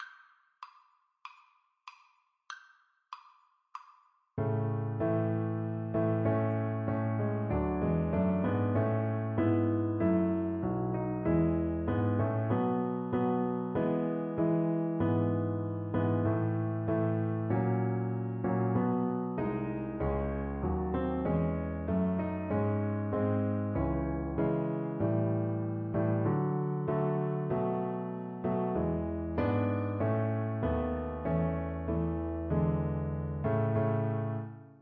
Play (or use space bar on your keyboard) Pause Music Playalong - Piano Accompaniment Playalong Band Accompaniment not yet available transpose reset tempo print settings full screen
Not too slow = c. 96
A major (Sounding Pitch) (View more A major Music for Violin )